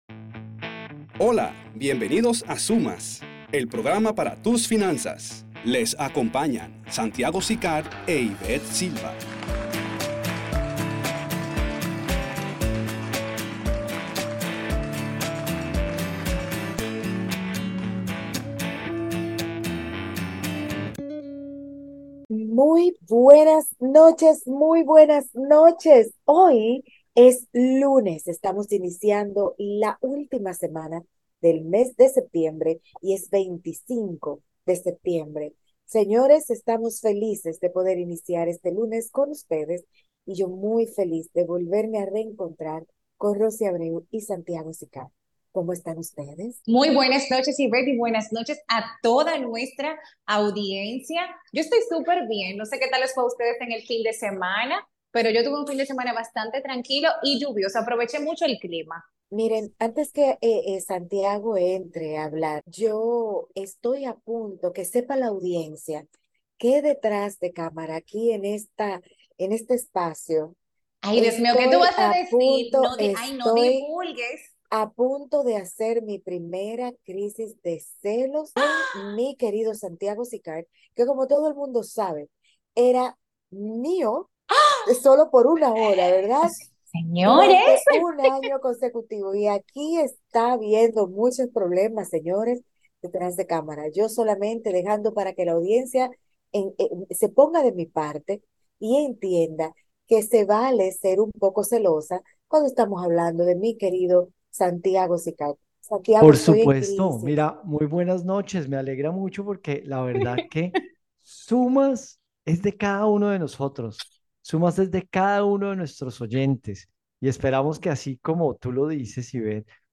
Ecucha todo nuestro programa de radio de hoy.